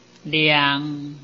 臺灣客語拼音學習網-客語聽讀拼-海陸腔-鼻尾韻
拼音查詢：【海陸腔】liang ~請點選不同聲調拼音聽聽看!(例字漢字部分屬參考性質)